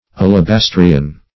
Alabastrian \Al`a*bas"tri*an\, a.